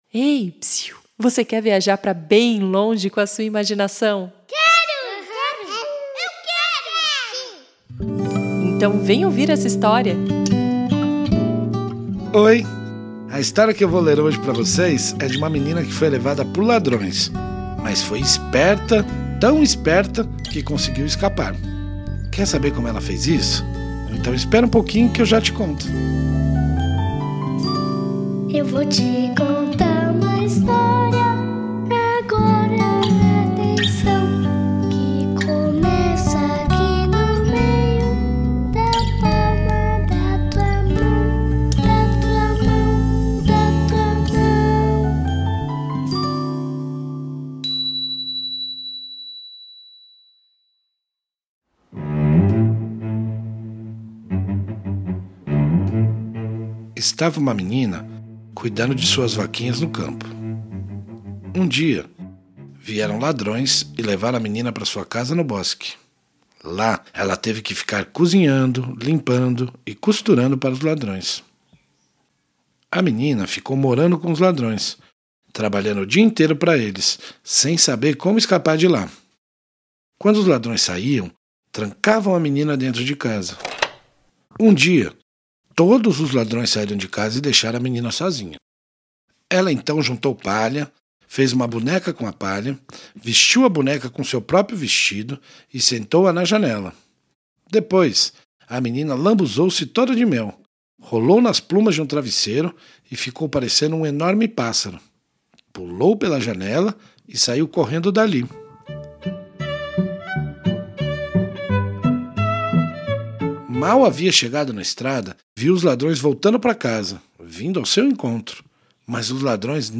Este é um conto rítmico, indicado para as crianças menores.